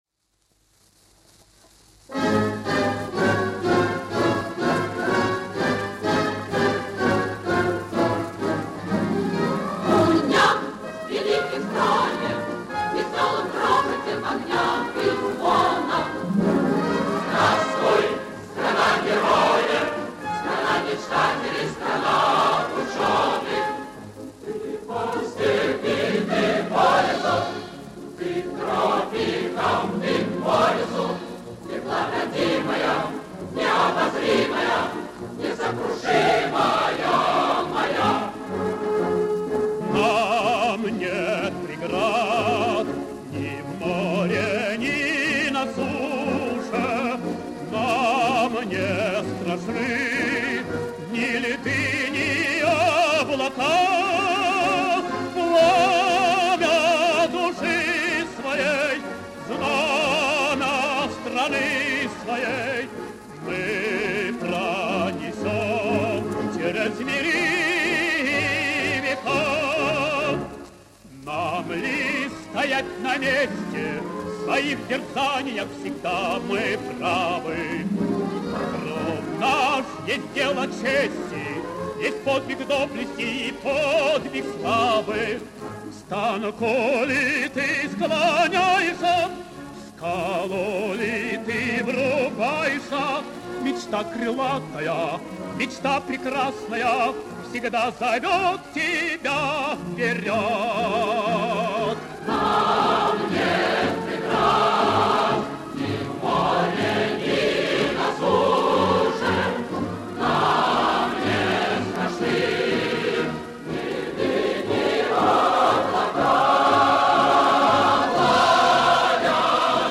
Ансамбль песни